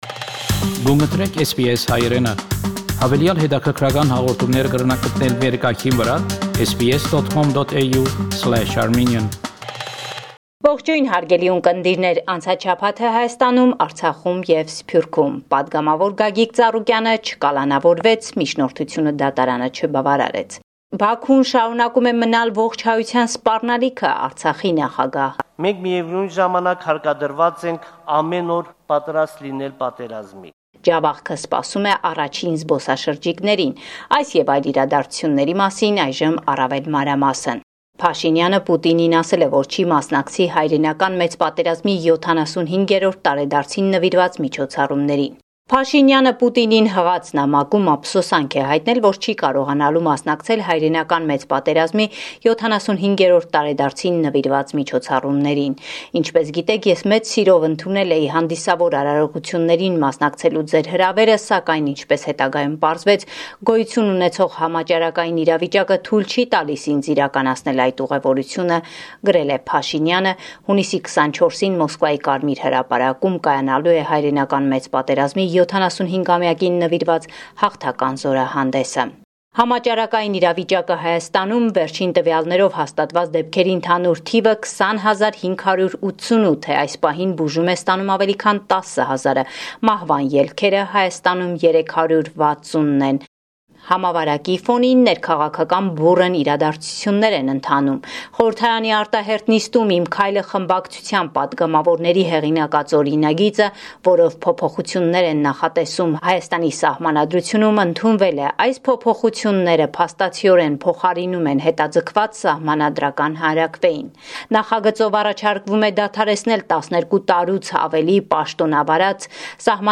Latest News from Armenia – 23 June 2020